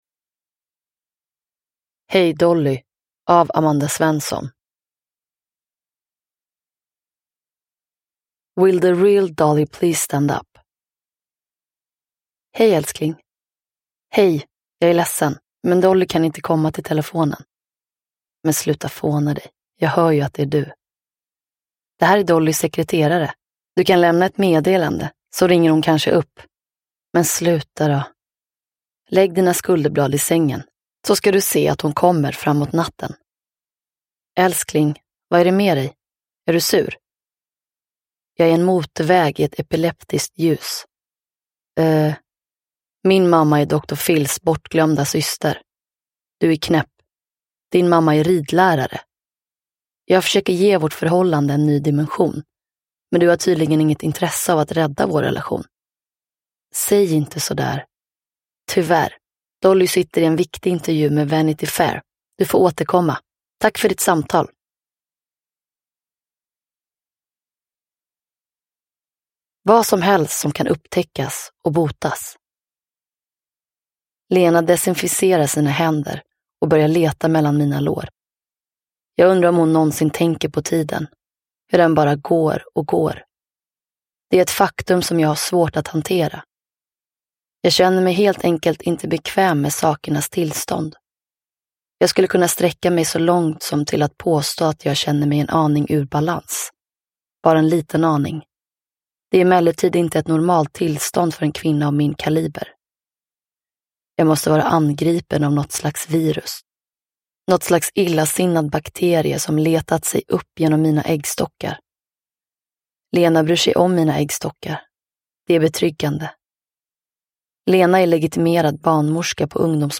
Hey Dolly – Ljudbok – Laddas ner
Uppläsare: Gizem Erdogan